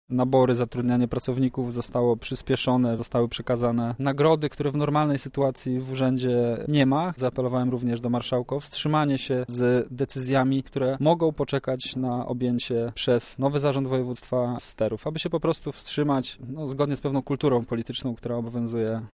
– mówi Michał Mulawa, radny klubu Prawo i Sprawiedliwość